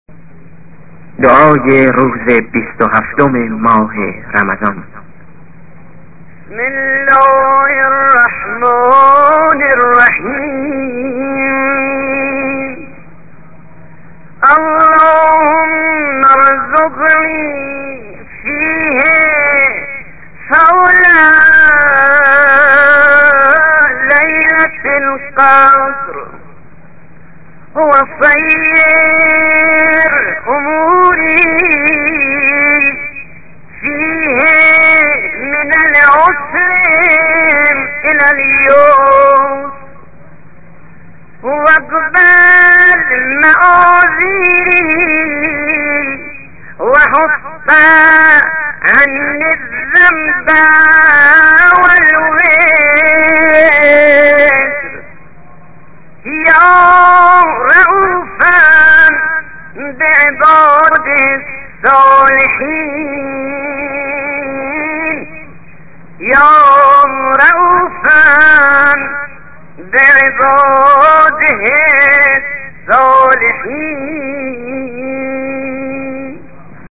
ادعية أيام شهر رمضان